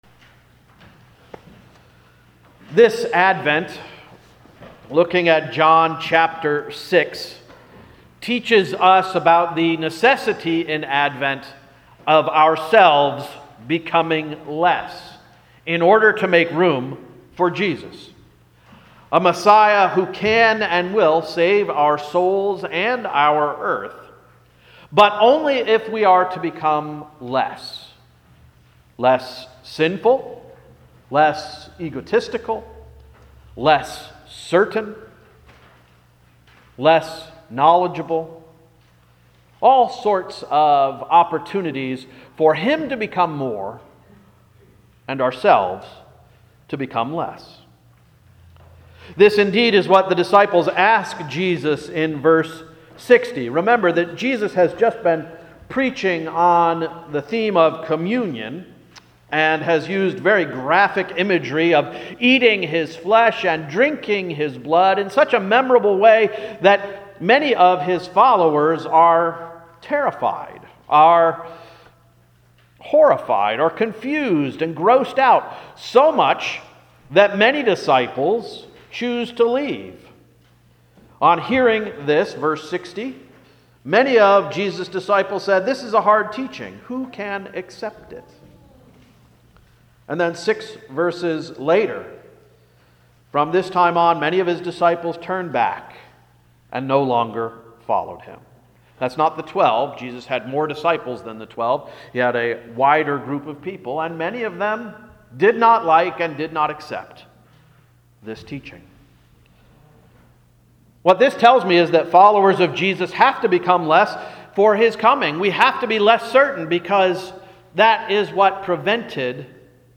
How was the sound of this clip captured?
December 3, 2017 Sermon — “The Holy One of God” – Emmanuel Reformed Church of the United Church of Christ